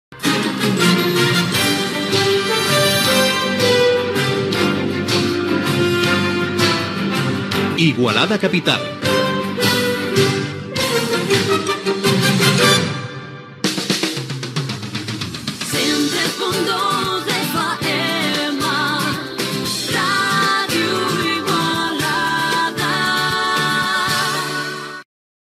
Indicatiu del programa i de l'emissora